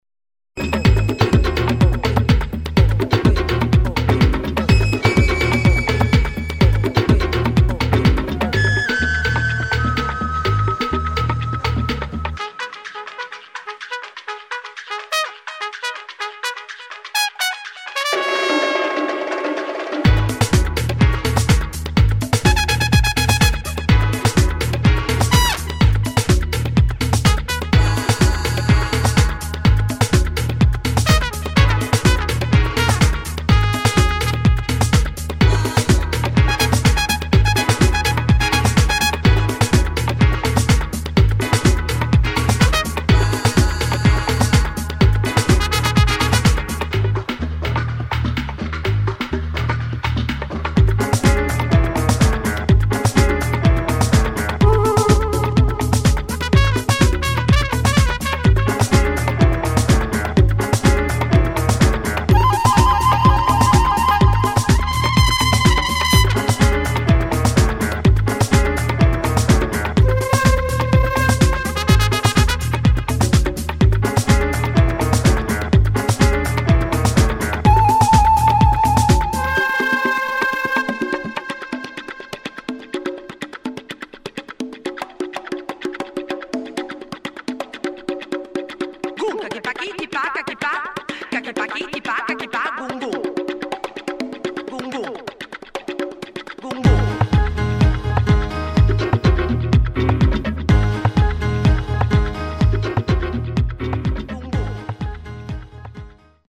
[ JAZZ / AFRO / CUBAN ]